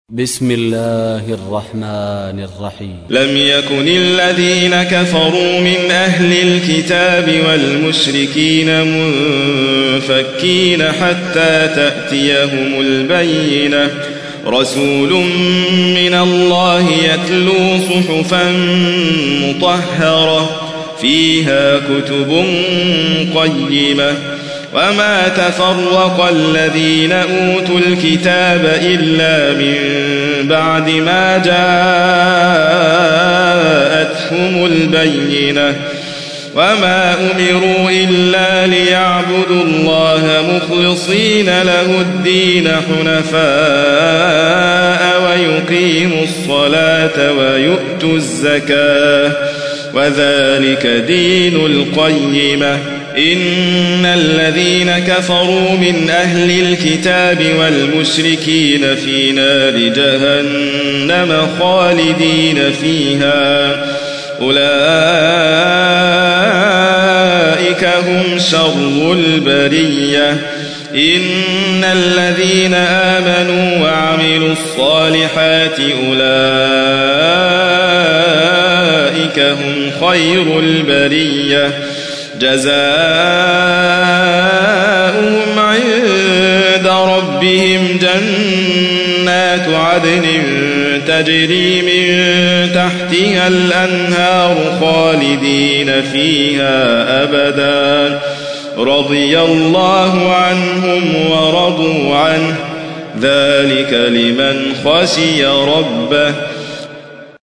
تحميل : 98. سورة البينة / القارئ حاتم فريد الواعر / القرآن الكريم / موقع يا حسين